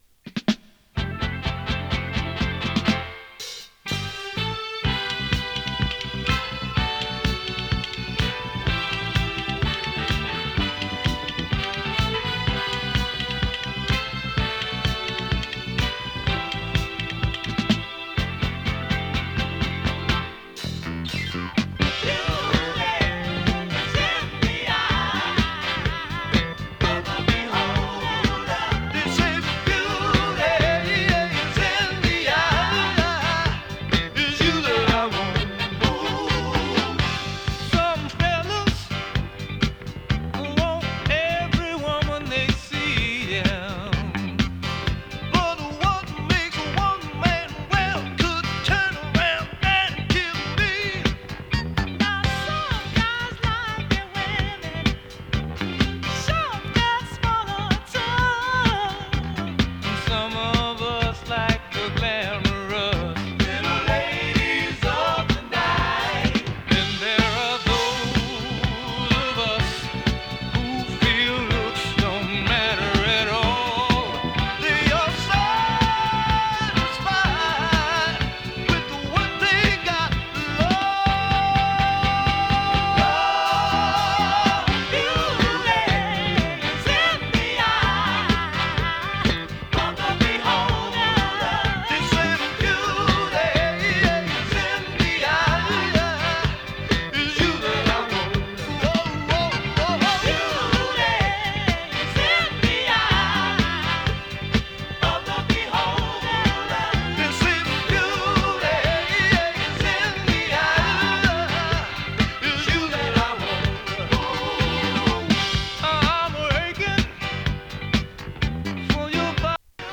フィリーソウル
多彩なコーラス・ワークがすばらしいスウイート/フィリーソウル！